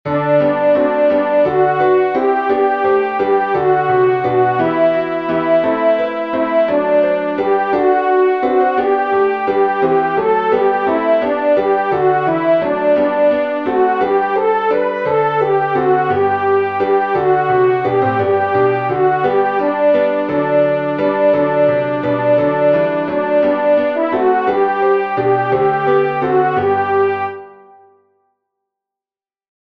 tomorrow_shall_be-alto-1.mp3